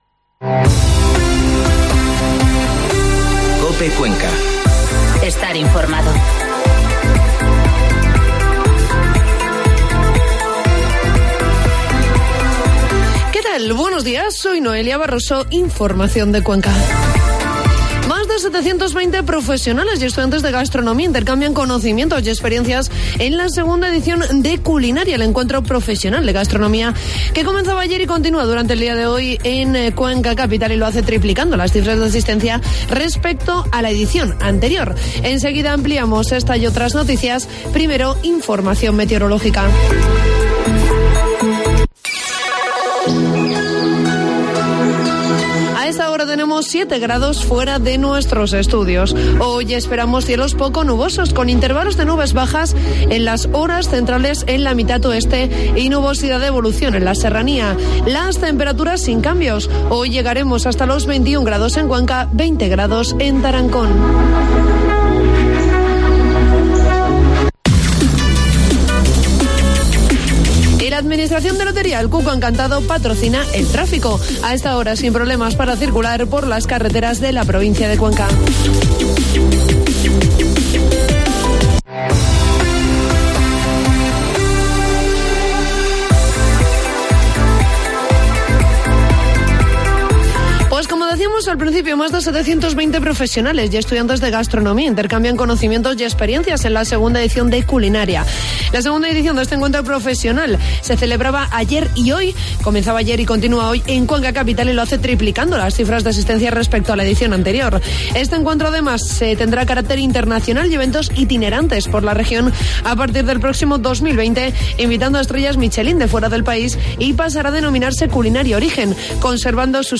Informativo matinal COPE Cuenca 29 de octubre